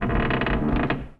metal_low_creak_squeak_04.wav